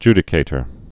(jdĭ-kātər)